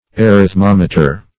Search Result for " arithmometer" : The Collaborative International Dictionary of English v.0.48: Arithmometer \Ar`ith*mom"e*ter\, n. [Gr.